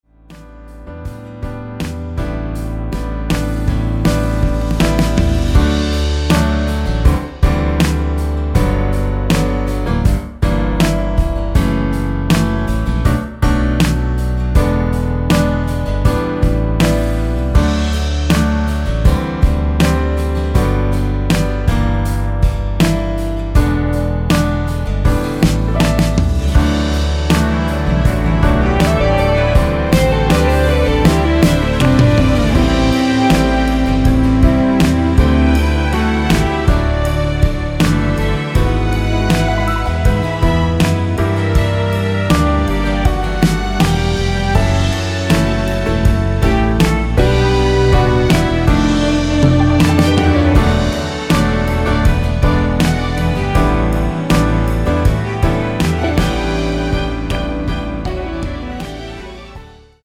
엔딩이 페이드 아웃이라 라이브 하시기 좋게 엔딩을 만들어 놓았습니다.
원키에서(-2)내린 2절 삭제 MR 입니다.(미리듣기및 아래의 가사 참조)
앞부분30초, 뒷부분30초씩 편집해서 올려 드리고 있습니다.
중간에 음이 끈어지고 다시 나오는 이유는